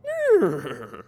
neigh.wav